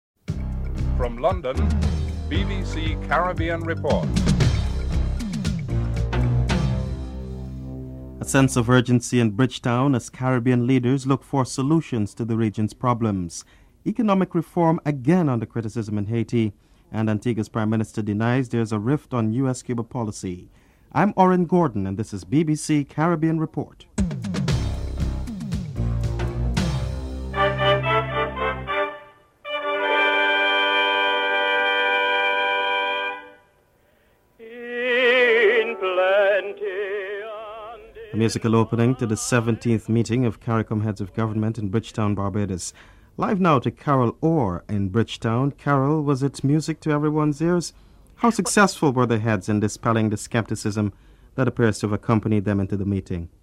1. Headlines (00:00-00:26)
2. A sense of urgency in Bridgetown as Caribbean leaders look for solutions to the region's problems. Edwin Carrington, Secretary-General of the Caribbean Community, President Chedi Jagan and Prime Minister Vaughn Lewis are interviewed (00:27-05:34)